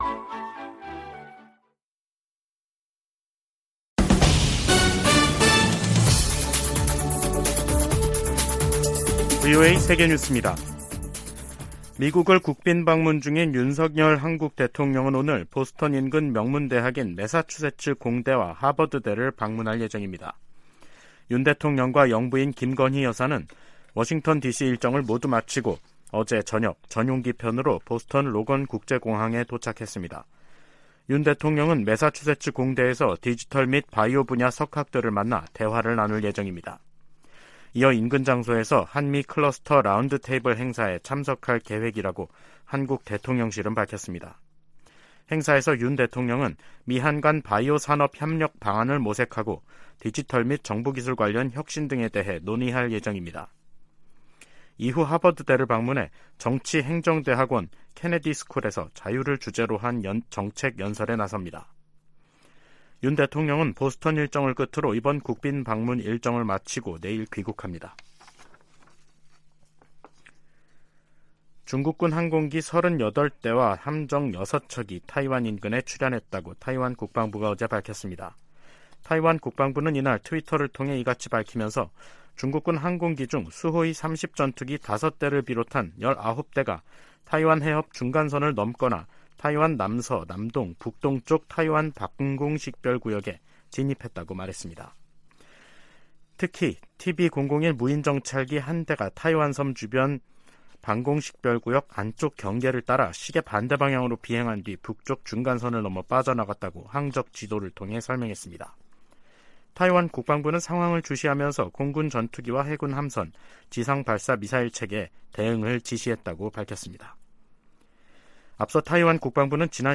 VOA 한국어 간판 뉴스 프로그램 '뉴스 투데이', 2023년 4월 28일 2부 방송입니다. 윤석열 한국 대통령은 27일 미 상·하원 합동회의 연설에서 북한 도발에 단호히 대응할 것이라며 미한 공조와 미한일 협력의 중요성을 강조했습니다.